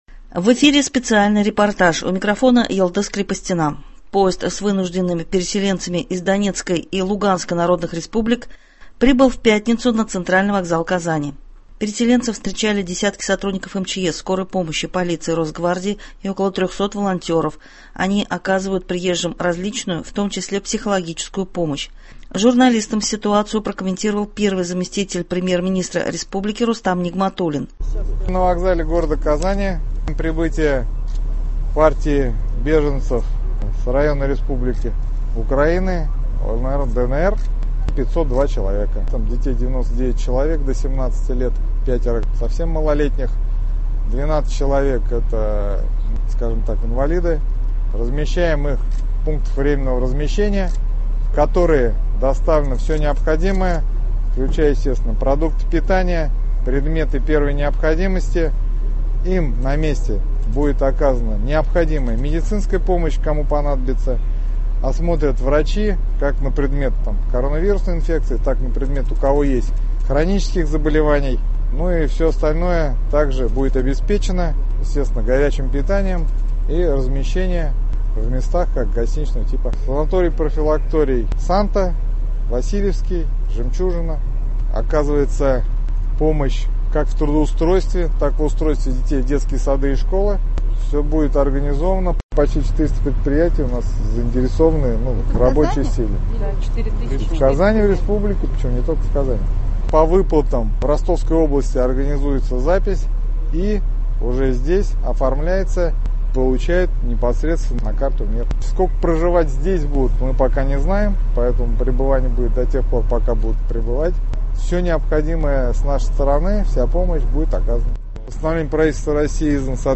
Специальный репортаж (02.04.22)
Поезд с вынужденными переселенцами из ДНР и ЛНР прибыл ночью на центральный вокзал Казани. Переселенцев встречали десятки сотрудников МЧС, скорой помощи, полиции, Росгвардии и порядка 300 волонтеров, которые оказывают приезжим различную, в том числе психологическую помощь. Журналистам ситуацию прокомментировал первый заместитель Премьер-министра республики Рустам Нигматуллин.